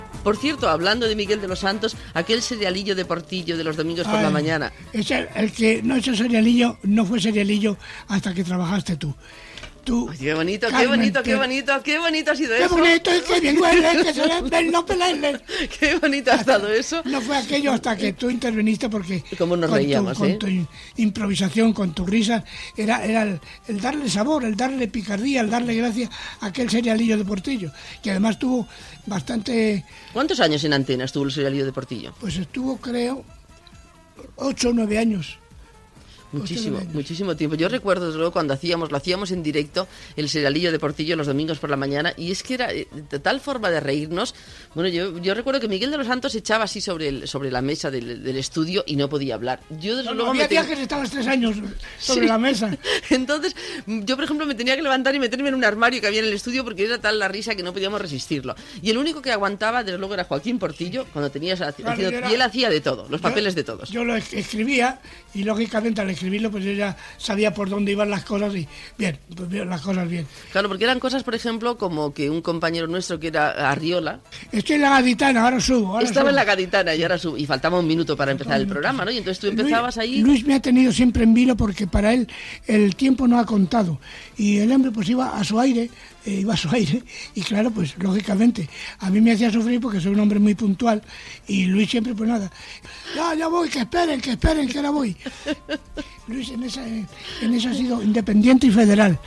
Entrevista a Joaquín Portillo "Top" sobre l'espai "El serialillo de Portillo" del programa "Hoy es domingo"